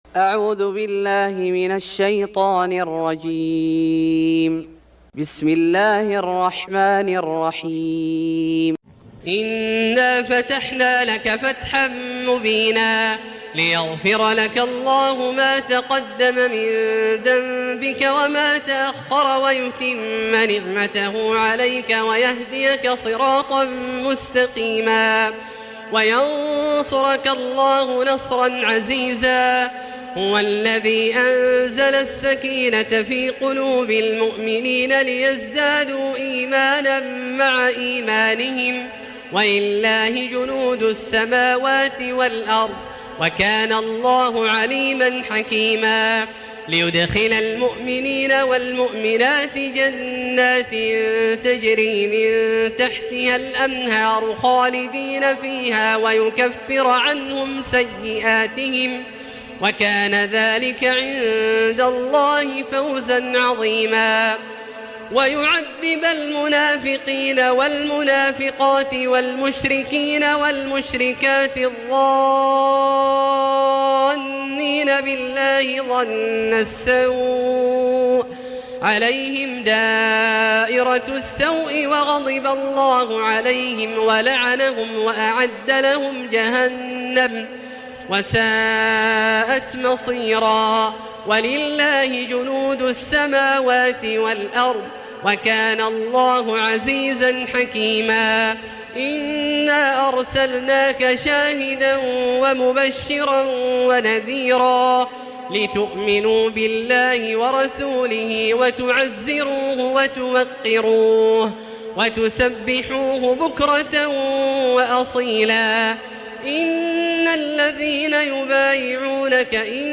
سورة الفتح Surat Al-Fath > مصحف الشيخ عبدالله الجهني من الحرم المكي > المصحف - تلاوات الحرمين